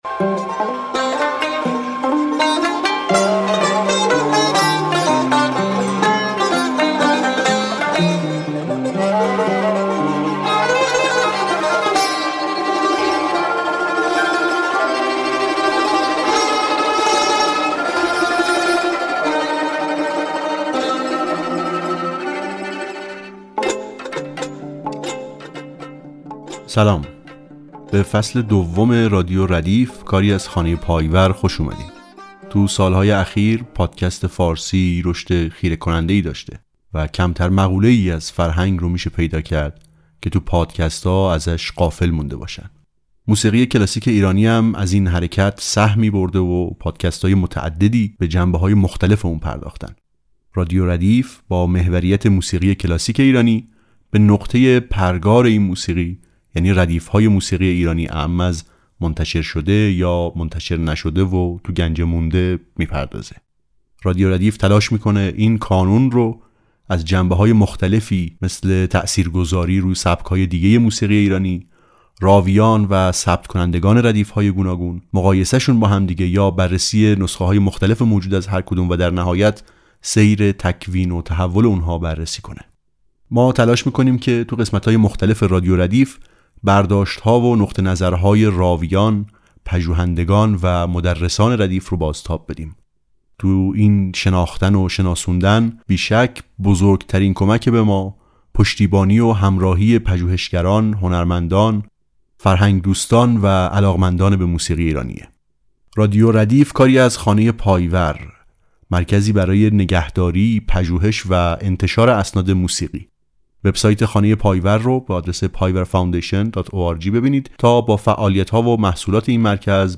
در کنار مرور تاریخی اسناد مکتوب، مجموعه‌ها و نمونه‌های شناخته‌شده و بازیافت شده نیز همراه پخش صداهایی از آنها معرفی شده‌اند.